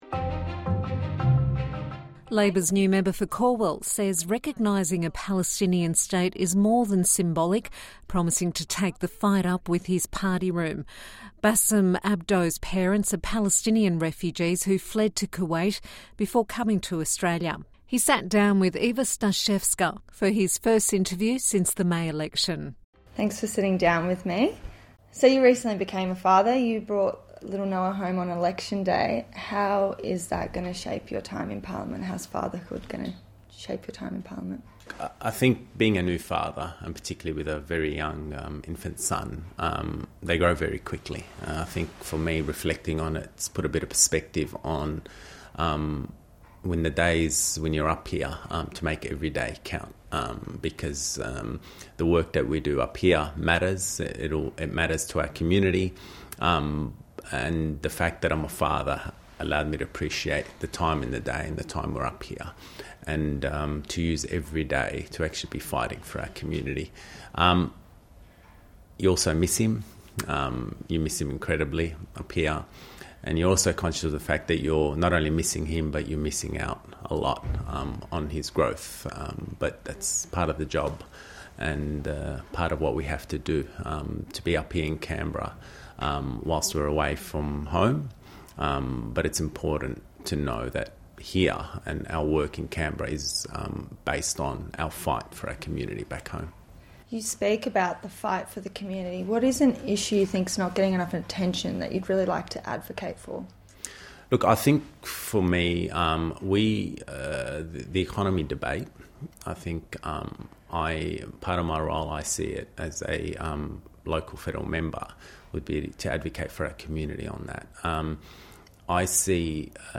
INTERVIEW: Basem Abdo on his Palestinian heritage and being a first-time federal MP
He sat down with SBS News for his first interview since the May election.